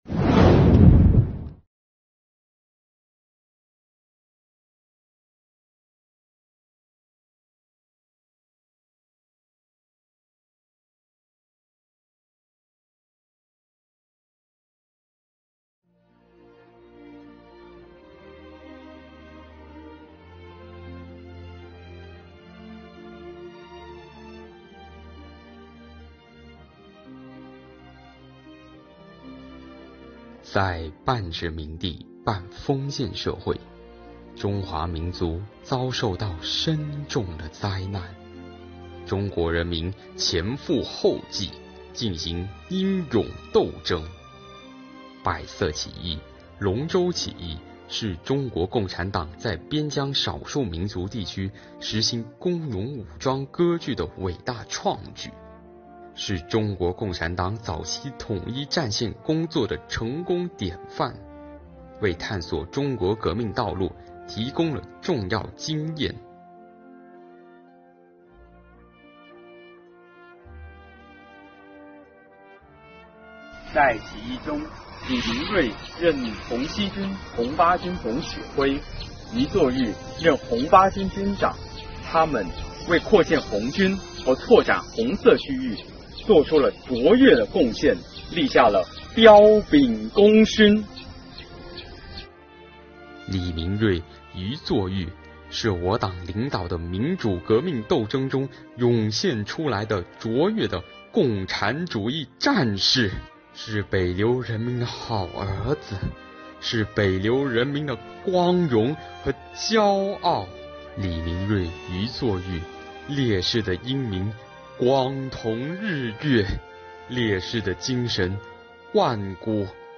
开展纪念碑碑文敬读活动，正是其中一项很有意义的活动。
在纪念园中，青年们参观了李明瑞俞作豫纪念馆，学习党史知识，开展党史研讨，在纪念碑前，青年党员们敬读英雄事迹，重温入党誓词，鞠躬致敬，表达对革命烈士的崇高敬意！